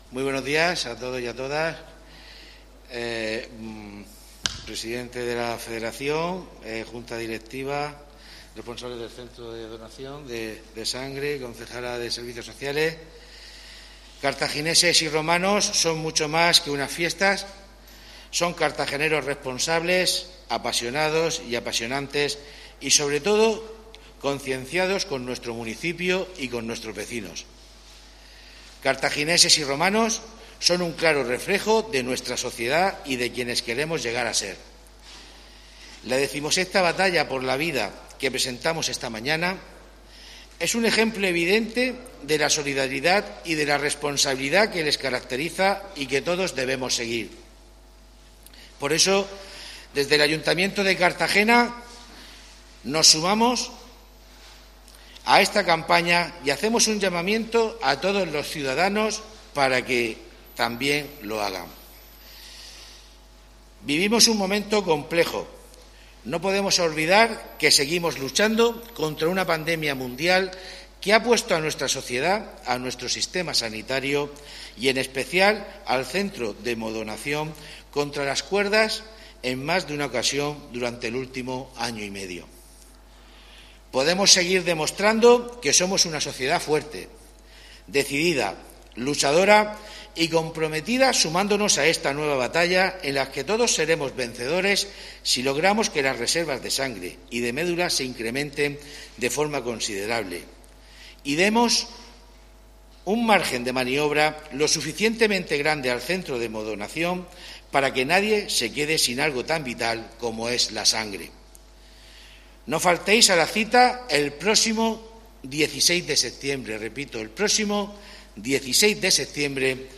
Enlace a Presentación de la XV Batalla por la Vida